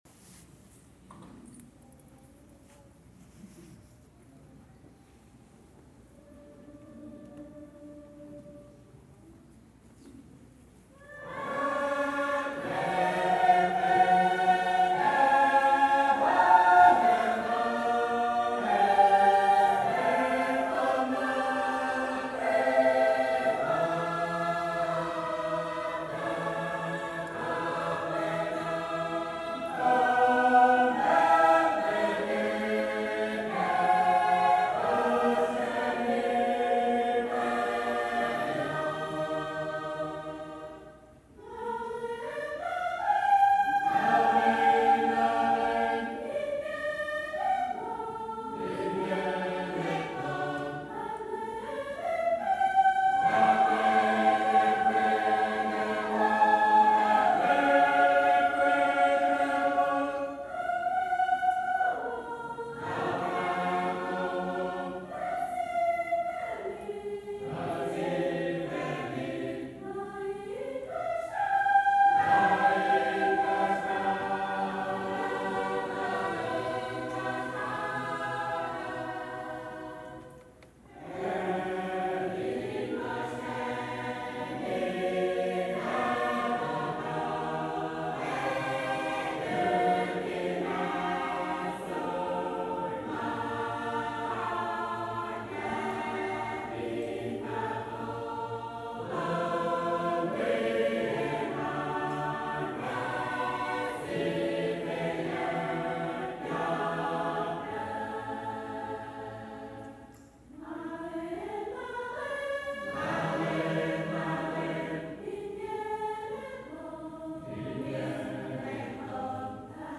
After a few songs from the hymnal, the choirs get up to sing in a series of progressively older groups, punctuated by speeches from the two pastors.
The singing itself is the highlight: the hymnals show the crazy attempt to transcribe the traditional melodies into western notation, with 8/6 and other odd time signatures and a staff full of sharps and flats on every line.  While some of the melodies are vaguely familiar, the overall effect is novel, with minor chord progressions that sound like nothing so much as the Doppler shift of music from a passing car.
[One of the Lelu Choirs singing; starts after about 10 seconds]
Adult-choir-kosrae.mp3